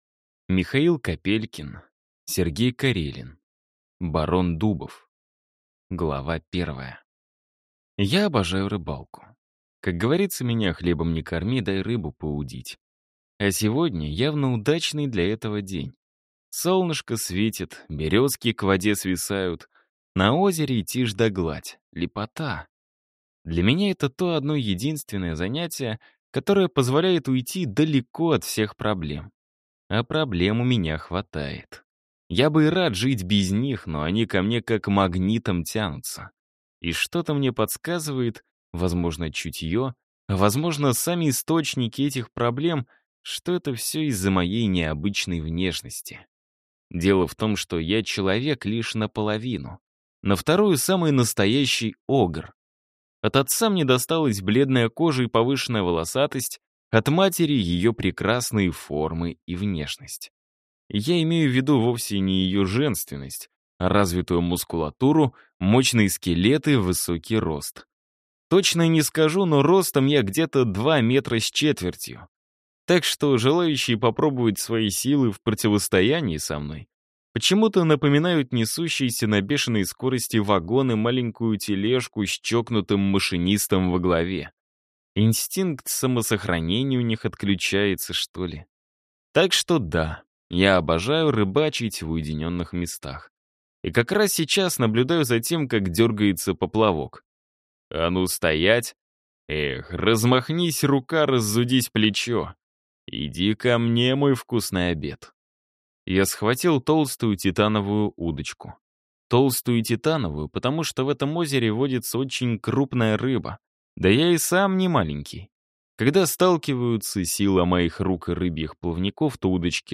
Давным-давно…2 Ходящая в тени (слушать аудиокнигу бесплатно) - автор Марина Леванова